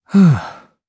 Siegfried-Vox_Sigh_jp.wav